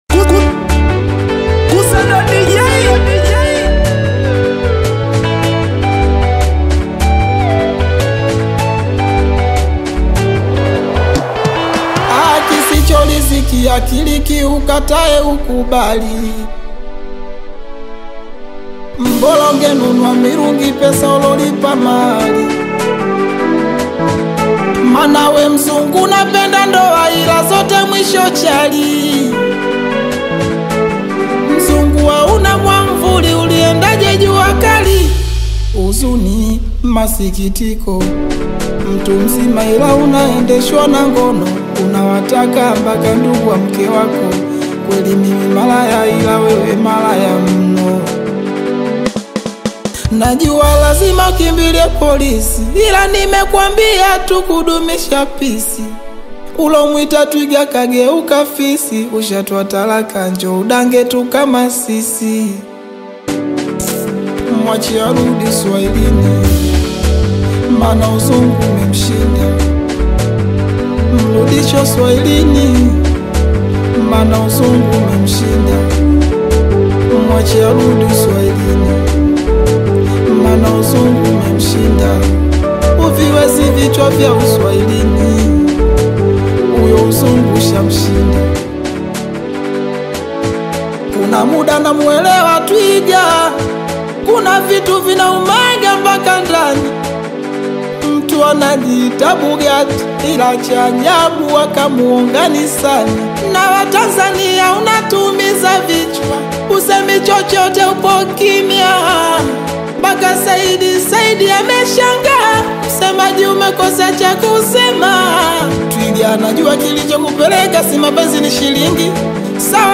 Genre: Singeli